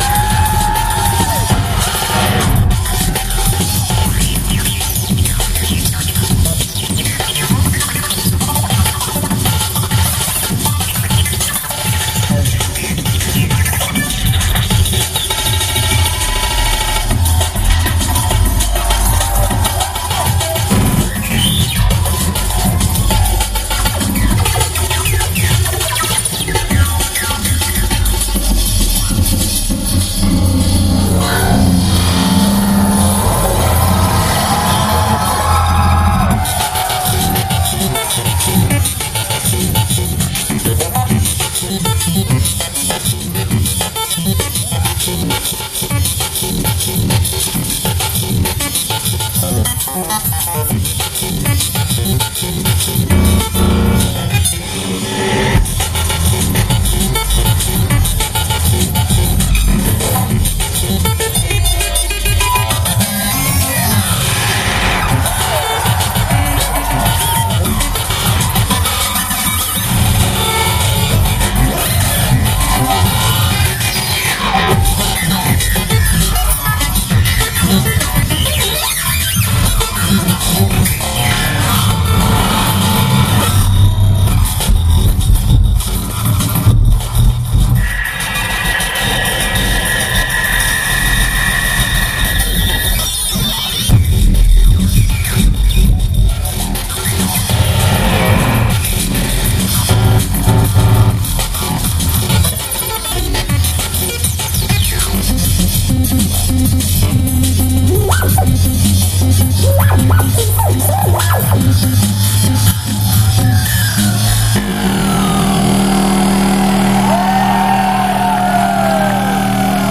location Tokyo, Japan venue Laforet Museum Roppongi